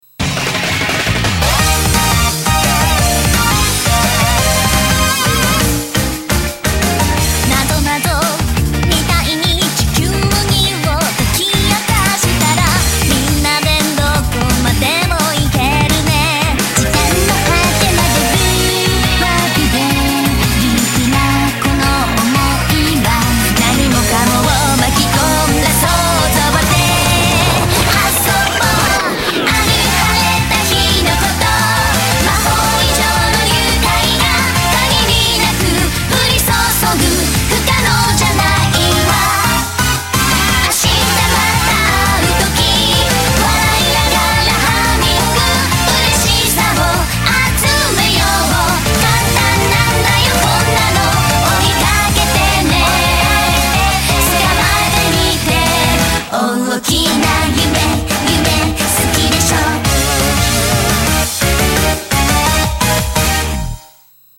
BPM172-172
Audio QualityCut From Video